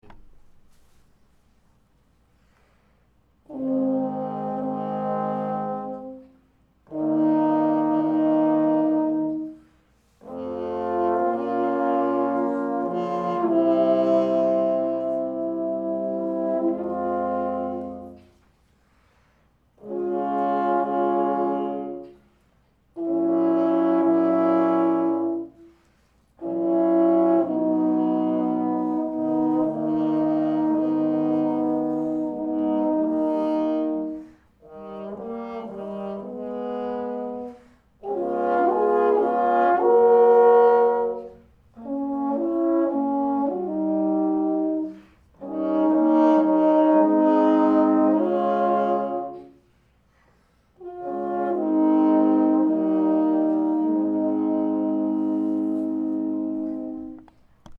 First, print out this PDF – it’s the score to the third movement of the Three Equali trombone quartet by Beethoven…
4. First part played baritone and fourth part played on cup mute